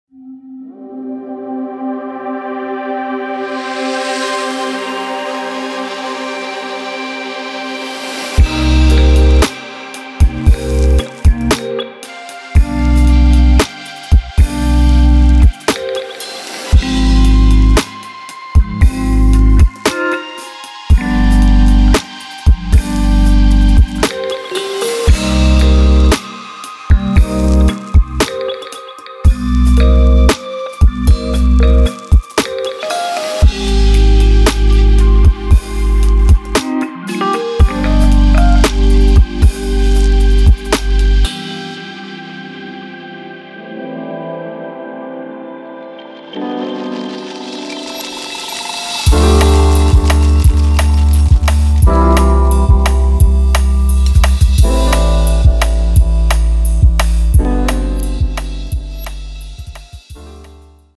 Live Sounds
* Over 645 High fidelity cymbal hits
* A combination of single hits, flourishes and chokes
* All hits used with sticks, brushes, mallets & rods